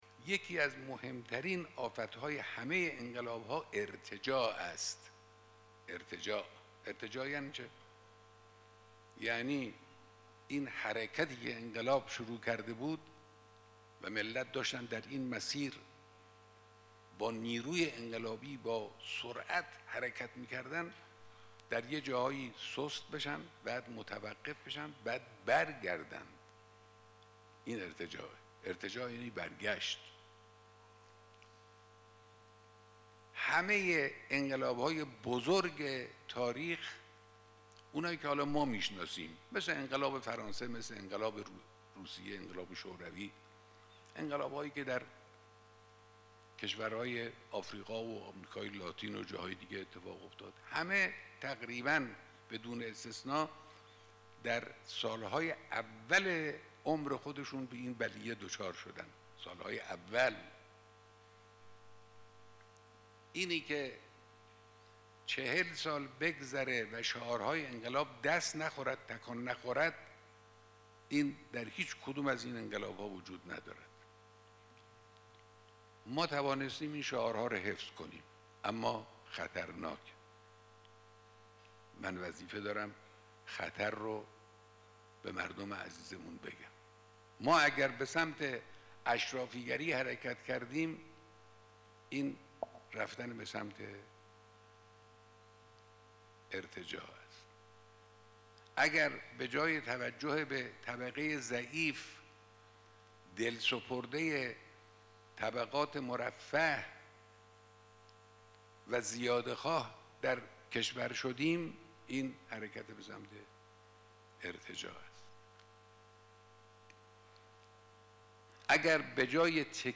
صوت/ گزیده بیانات رهبر معظم انقلاب در دیدار مردم استان آذربایجان شرقی
گزیده بیانات حضرت آیت الله خامنه‌ای در دیدار مردم استان آذربایجان شرقی در سالروز قیام ۲۹ بهمن ۵۶ مردم تبریز در ذیل قابل بهره برداری است. رهبر انقلاب در بخشی از سخنان خود فرمودند: اشرافی‌گری، اعتماد به بیگانه و بی‌توجهی به طبقات ضعیف، آفت انقلاب است.